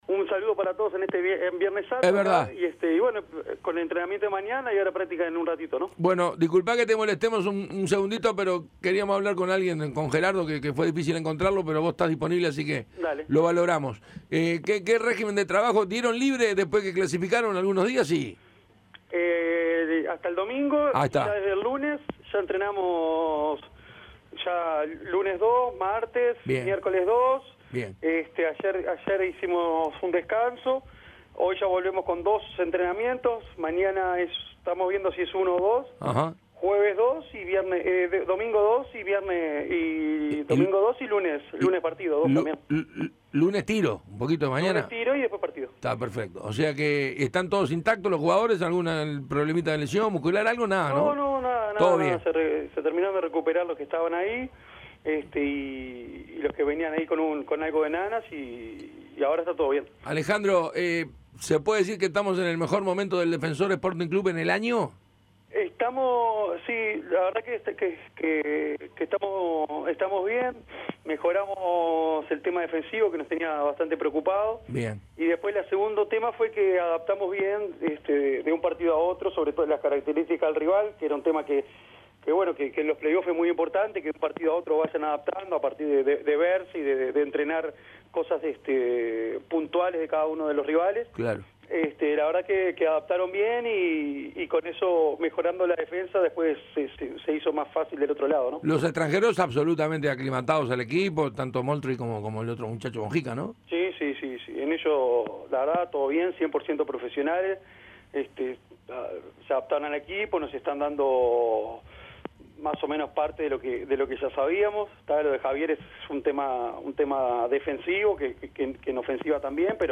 Audio de la entrevista completa.